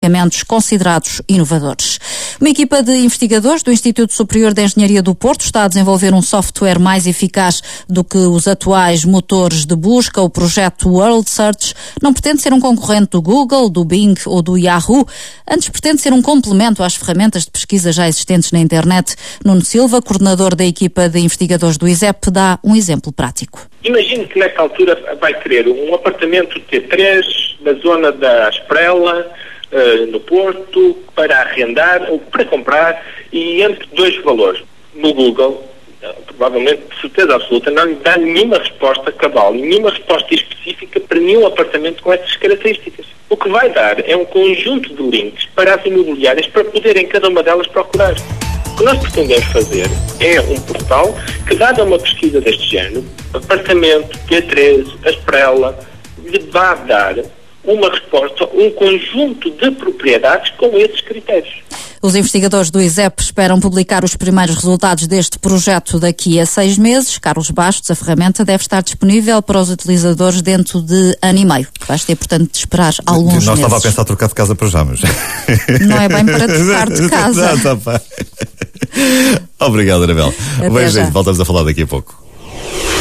world_search_entrevista_renascenca_CSP.mp3